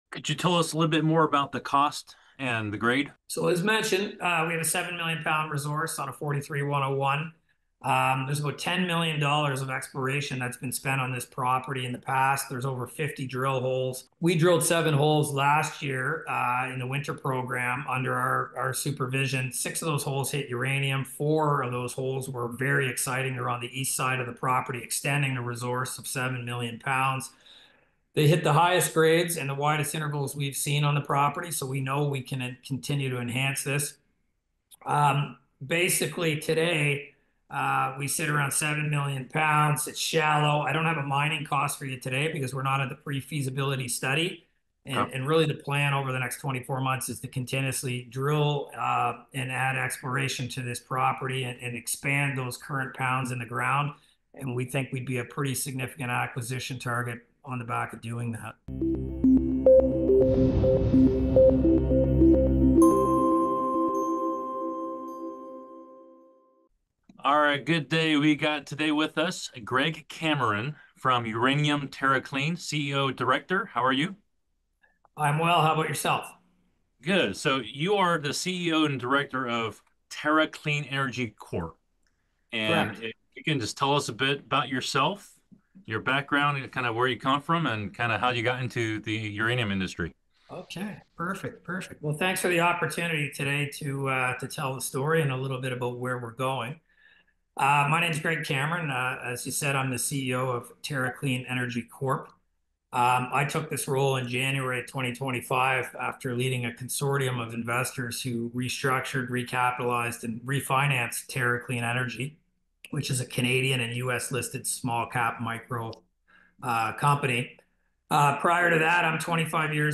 In this exclusive interview